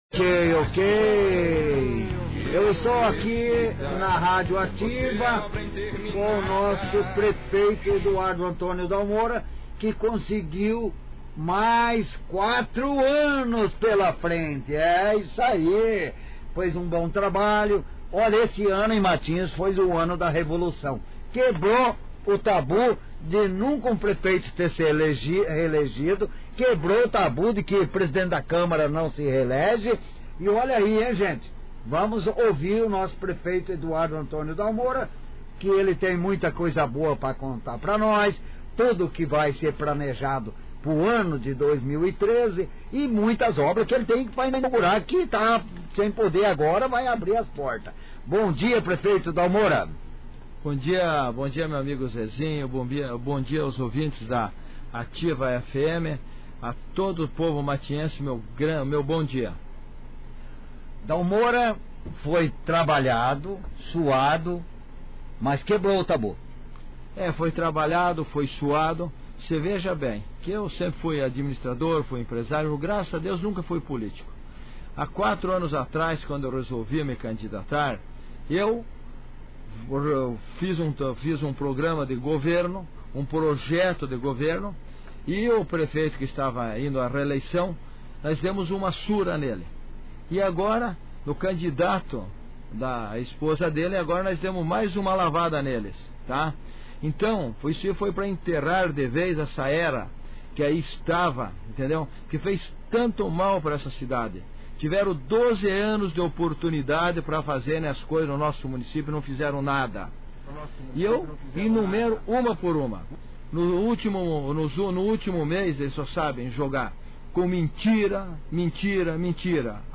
entrevista-dalmora-9out2012_UFPR.mp3